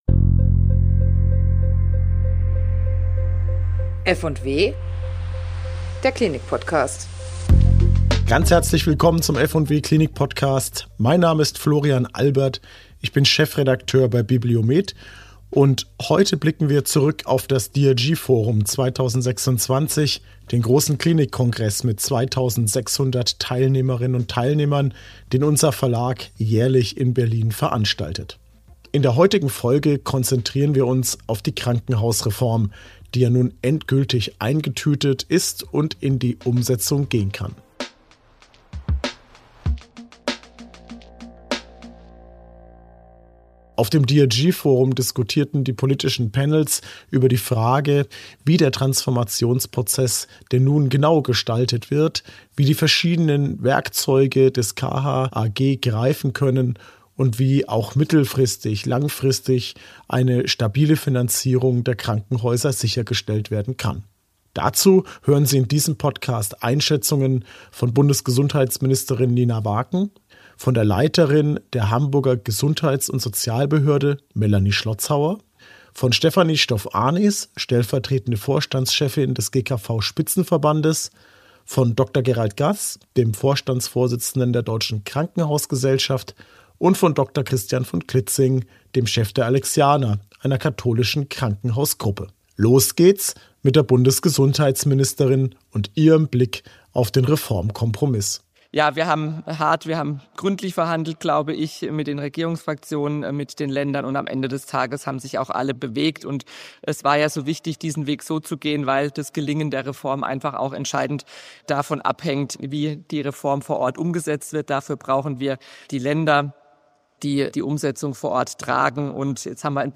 In dieser Folge bündeln wir die zentralen Einschätzungen vom DRG|FORUM 2026, bei dem Vertreterinnen und Vertreter aus Politik, Krankenkassen und Krankenhausträgerschaft ihre Positionen zur Umsetzung des KHAG erläuterten: Bundesgesundheitsministerin Nina Warken betont die Bedeutung realistischer Fristen und die Verantwortung der Länder für die Umsetzung vor Ort.